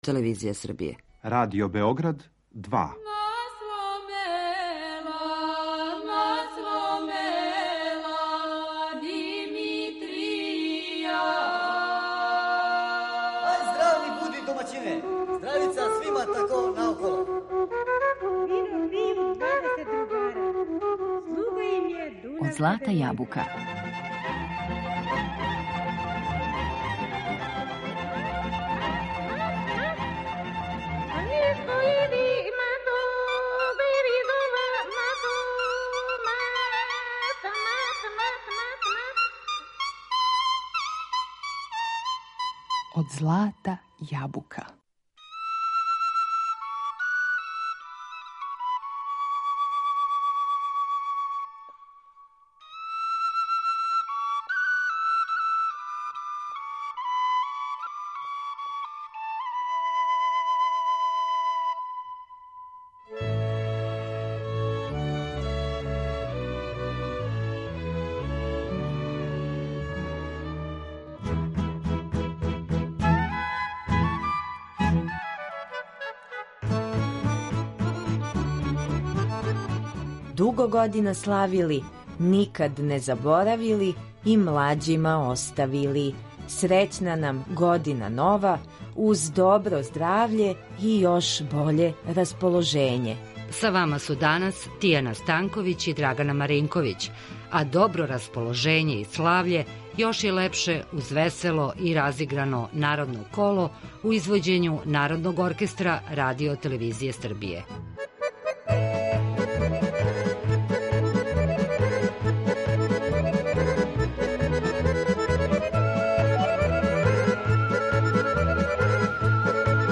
У данашњем издању емисије Од злата јабука , уз добру народну песму и кола нашим слушаоцима желимо све најбоље у Новој години.